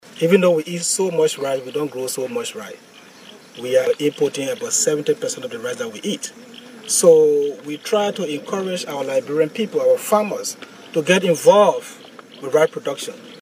On November 5, Agriculture Minister Alexander Neutah in a video interview aired on Kool FM’s Morning Ride program and posted on Facebook claimed that 70% of the rice consumed in Liberia is imported.